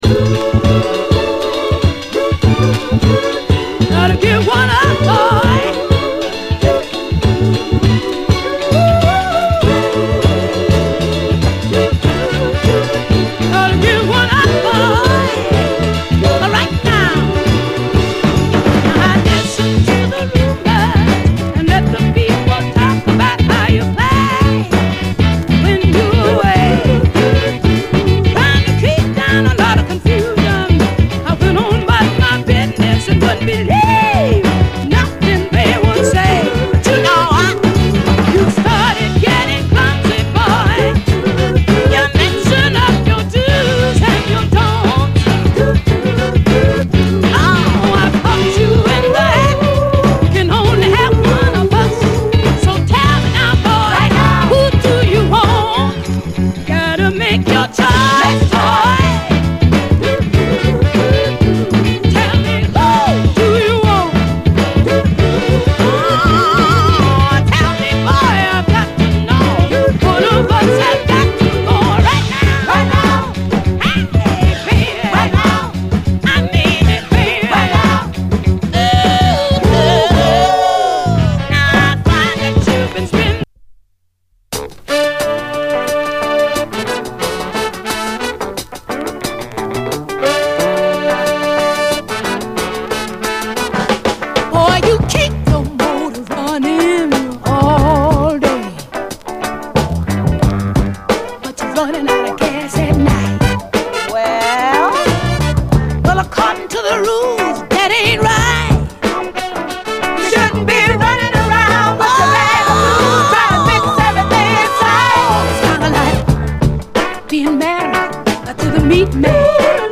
SOUL, 70's～ SOUL, 7INCH
溌剌ヤング・ソウル45！